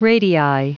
Prononciation du mot radii en anglais (fichier audio)
Prononciation du mot : radii